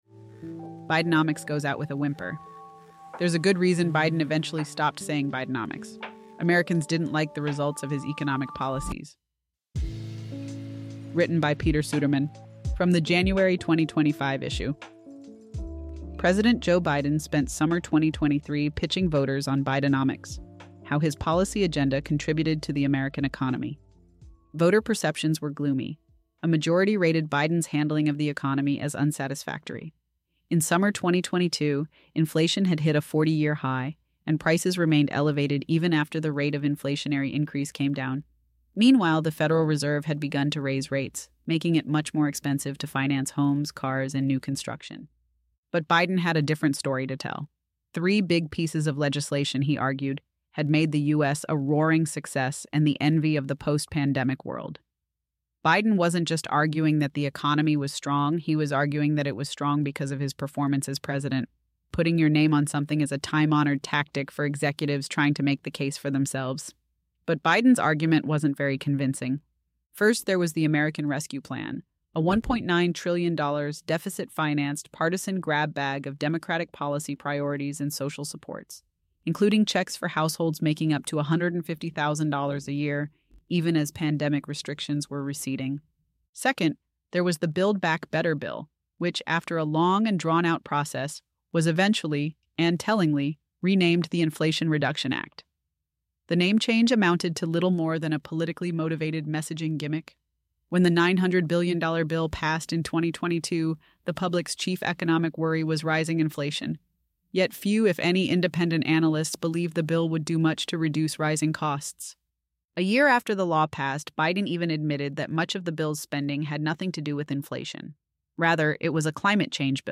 A weekly selection from Reason—the magazine of free minds and free markets—read by AI.